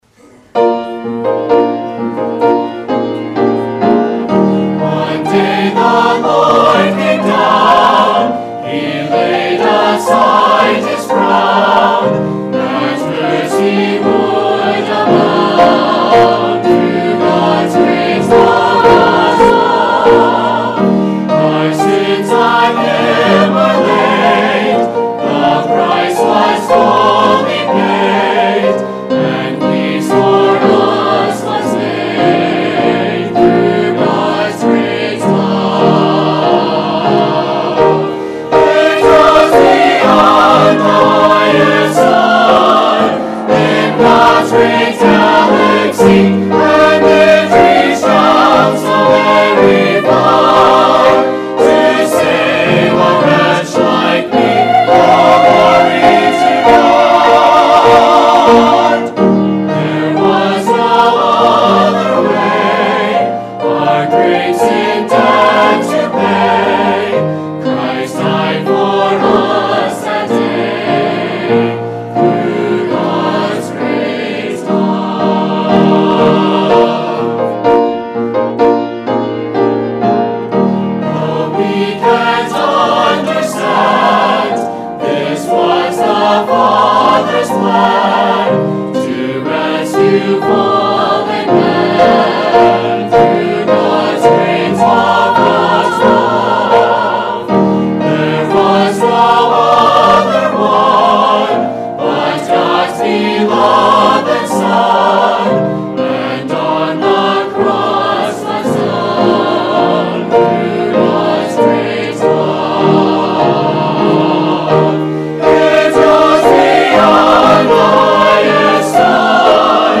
This week we open with a song from the Ambassador Baptist College Ensemble.